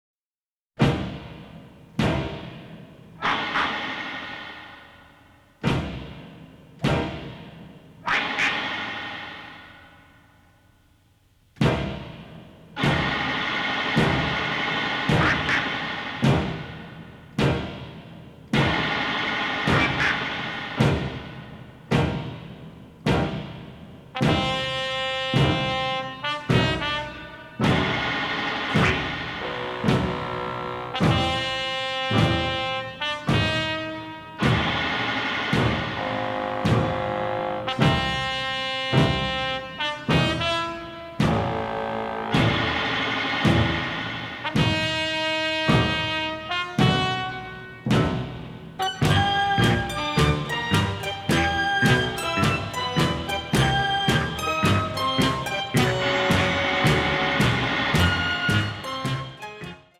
The Original Album (stereo)